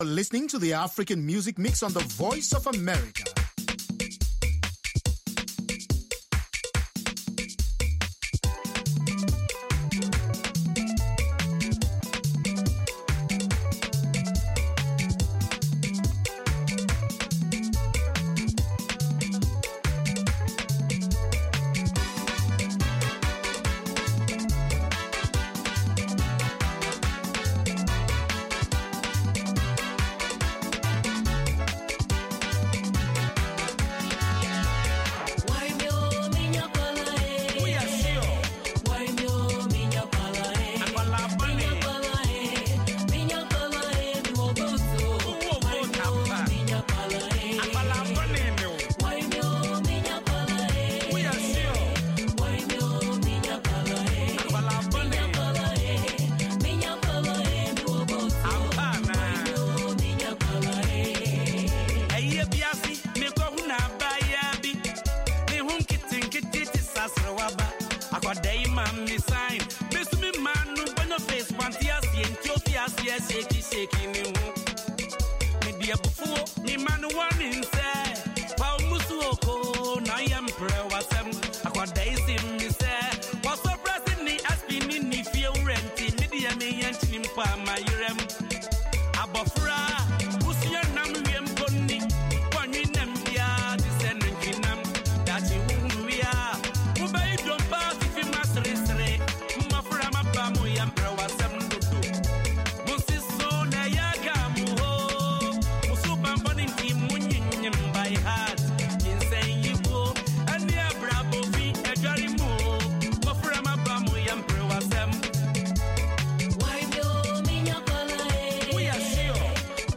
from N’dombolo to Benga to African Hip Hop
the best mix of pan-African music